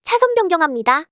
audio_lane_change.wav